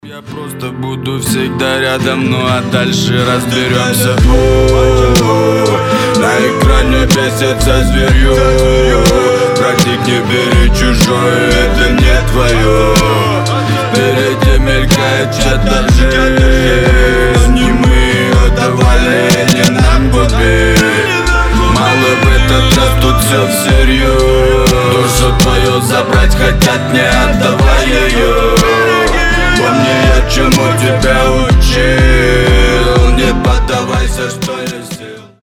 • Качество: 320, Stereo
гитара
душевные
сильные